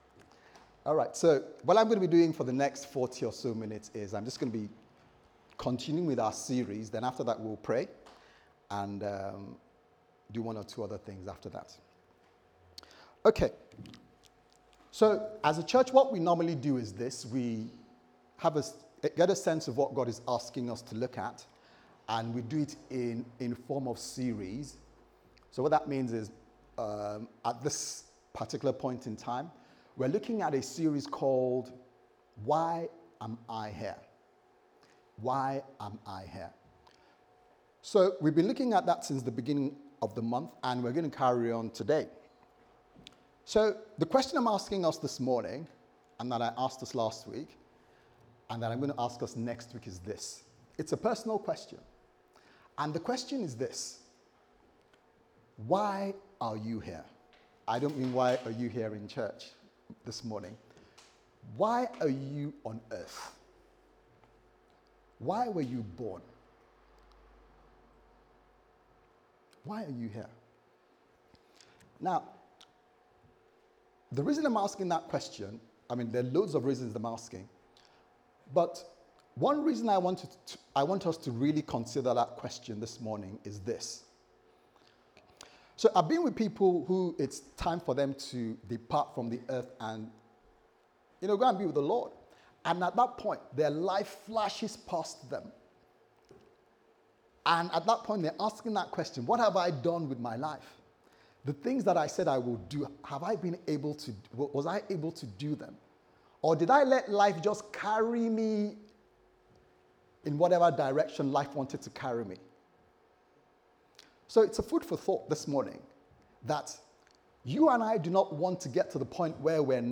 Why Am I Here Service Type: Sunday Service Sermon « Why Am I Here?